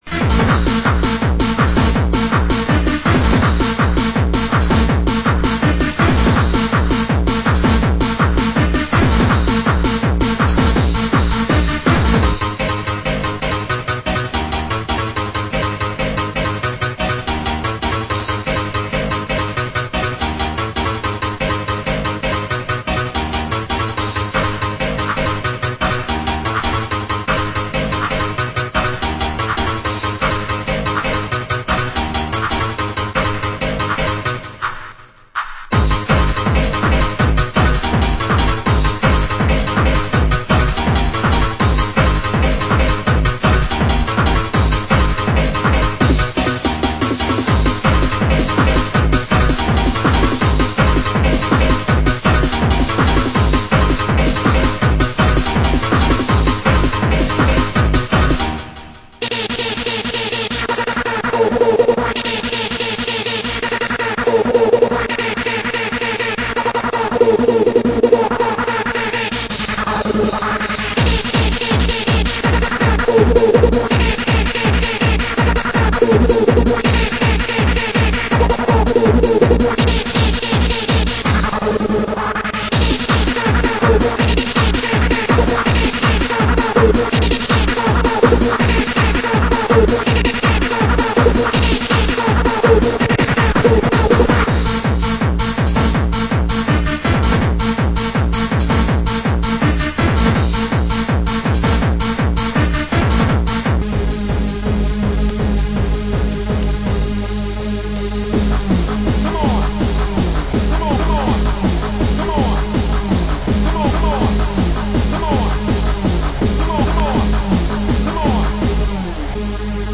Format: Vinyl 12 Inch
Genre: Happy Hardcore